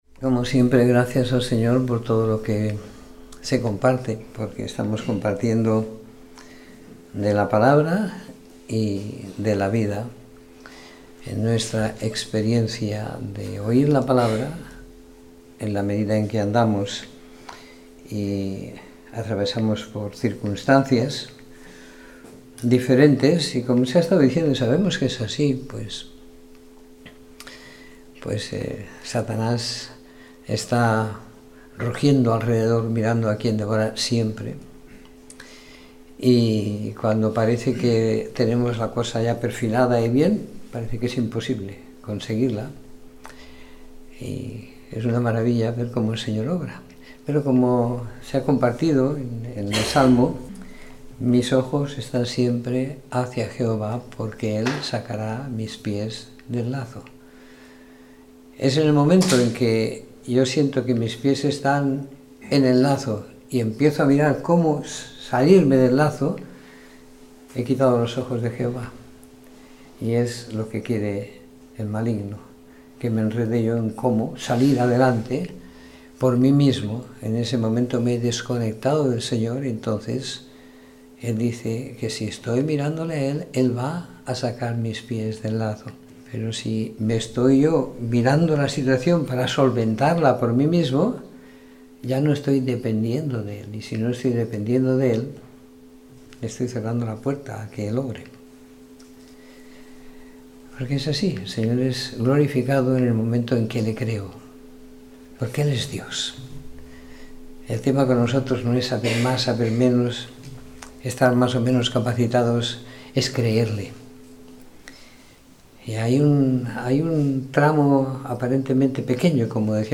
Domingo por la Tarde . 19 de Febrero de 2017